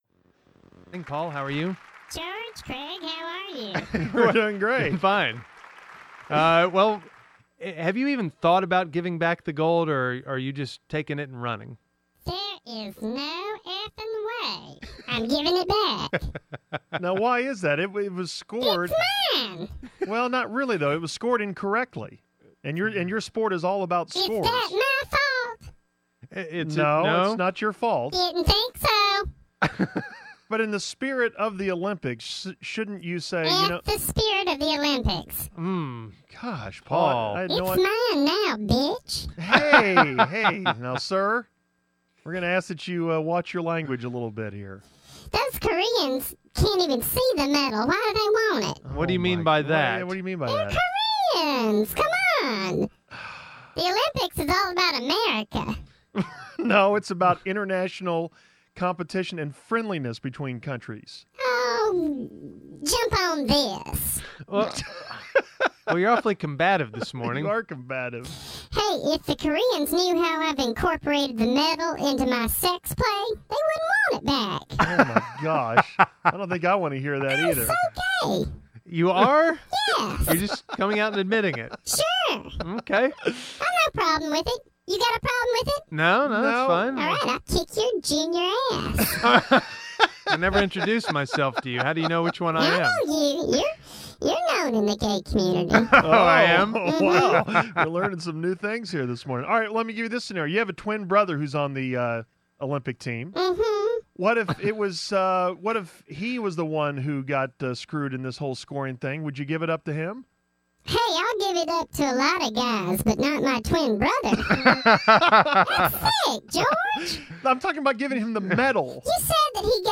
It’s the fake Paul Hamm and it sounds like the twin brother of a more current character